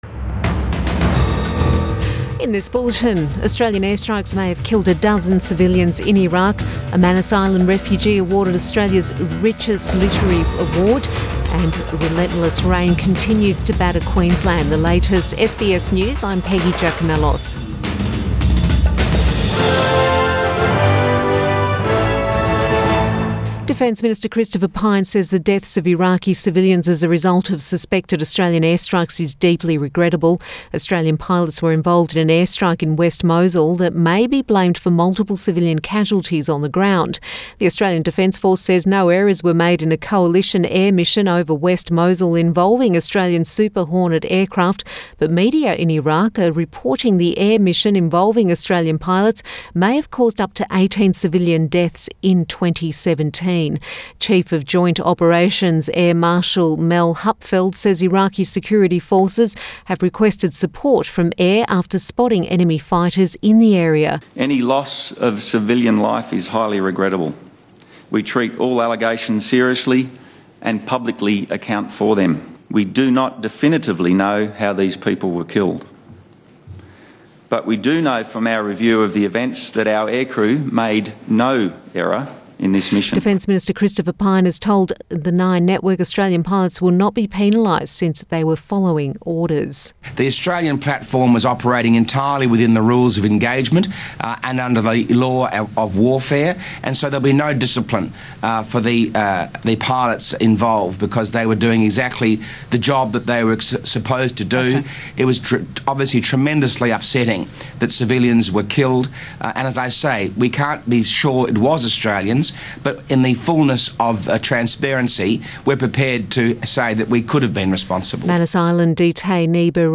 Midday Bulletin Feb 1